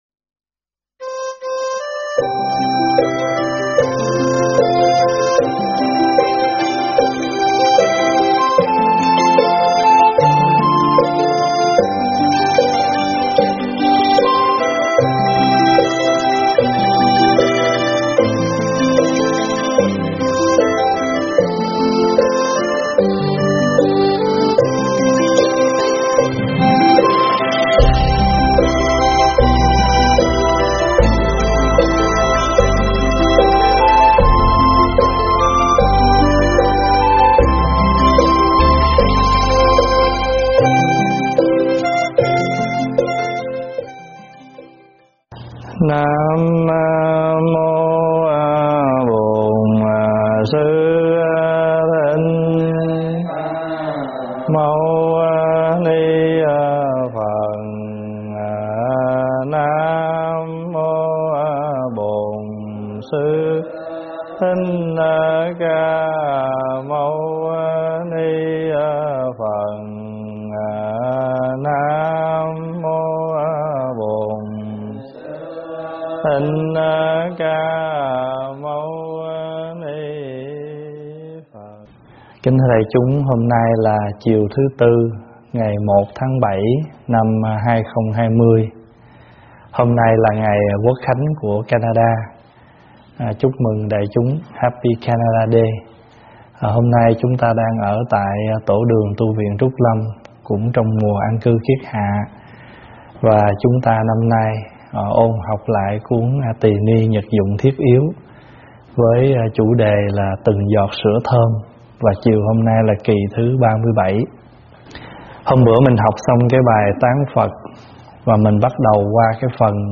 giảng tại Tv Trúc Lâm